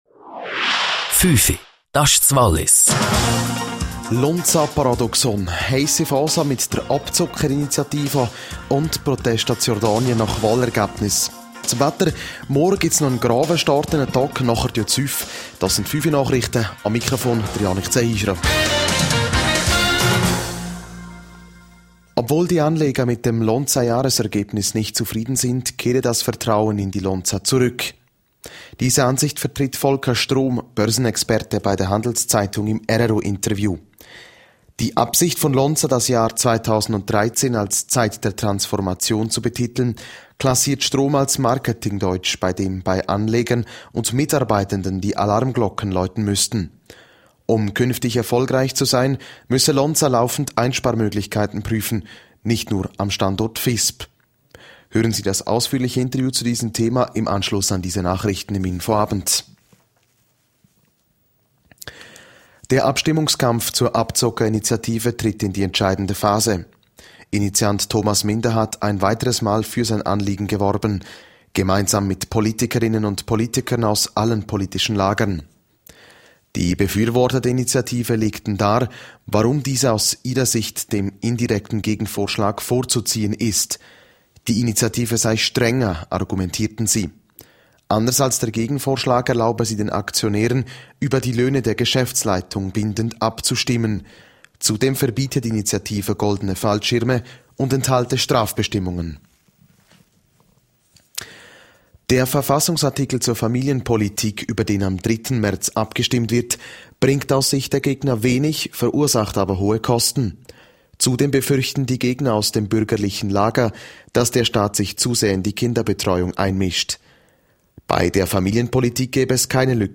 17:00 Uhr Nachrichten (6.18MB)